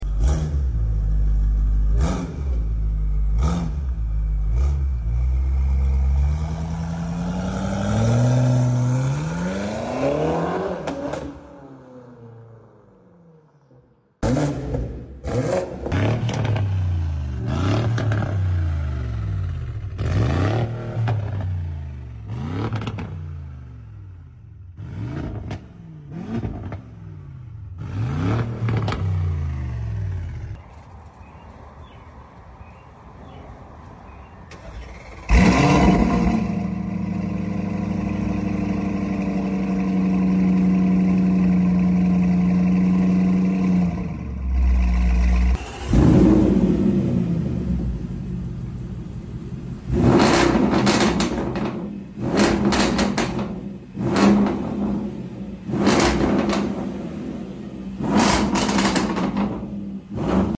V8 SUV Sound Battle Comment Sound Effects Free Download